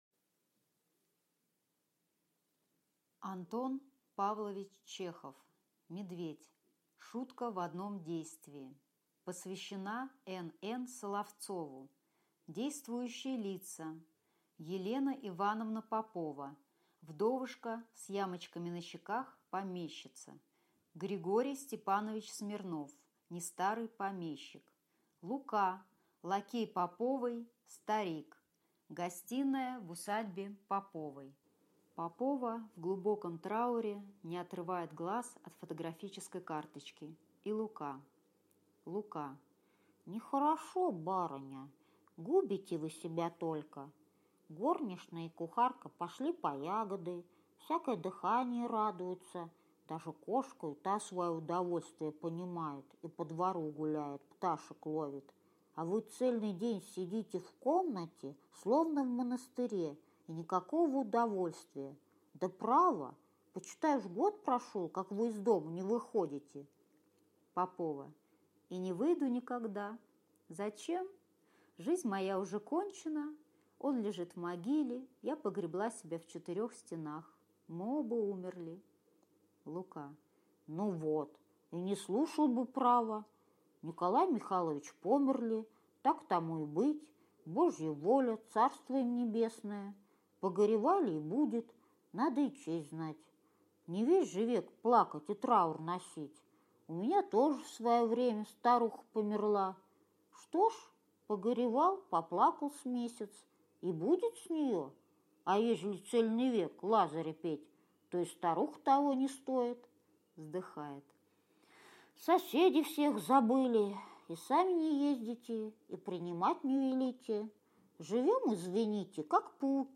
Аудиокнига Медведь | Библиотека аудиокниг